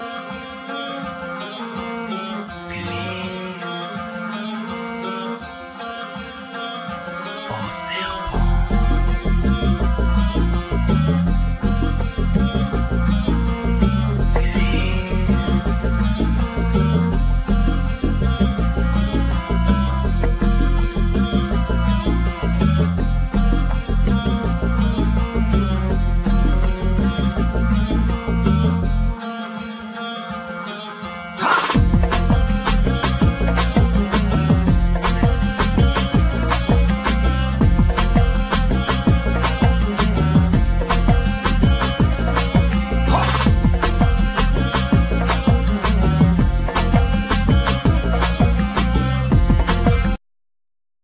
Vocal,Programming
Cello
Congas,Doundoun,Ti-bois
Kit percussions,Doudoumba
Gaida
Tin whistle
Dof